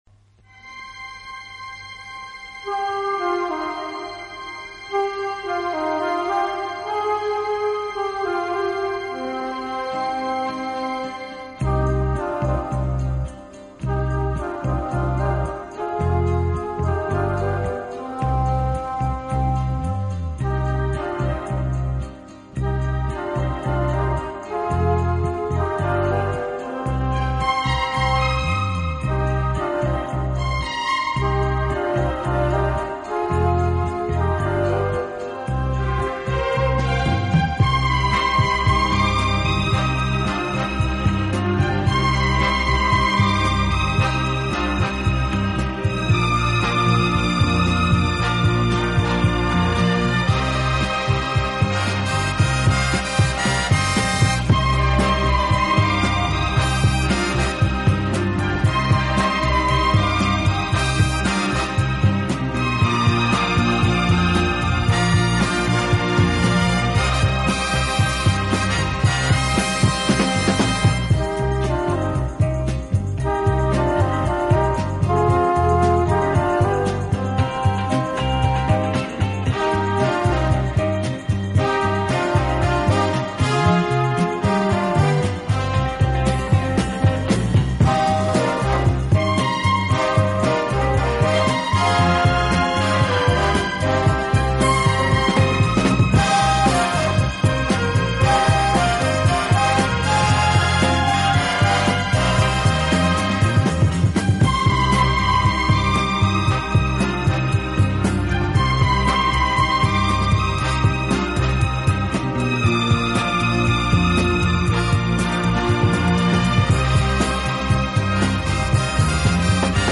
体，曲风浪漫、优雅，令人聆听時如感轻风拂面，丝丝柔情触动心扉，充分领略